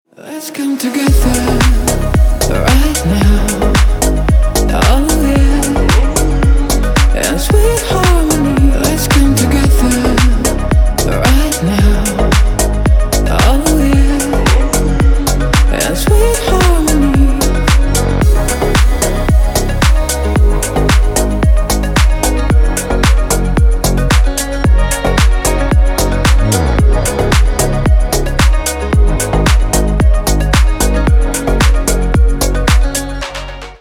кавер 2024 для рингтона